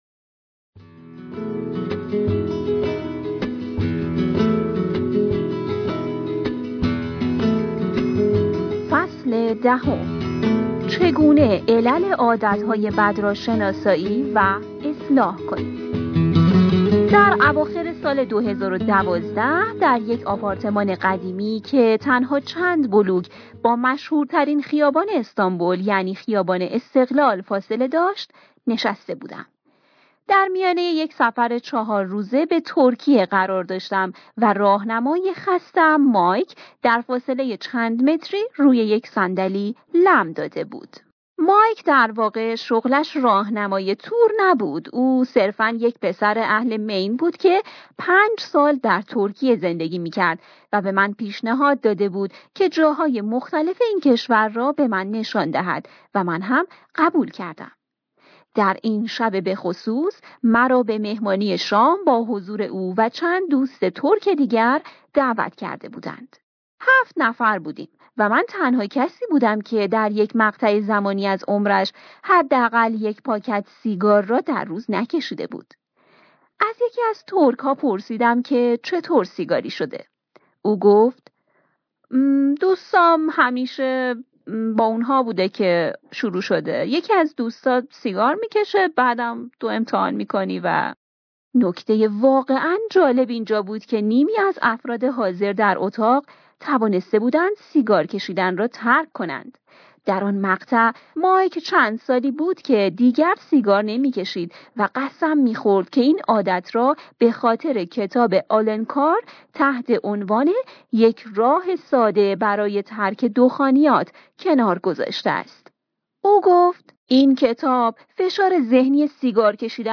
کتاب صوتی عادتهای اتمی (جیمز کلیر) (11)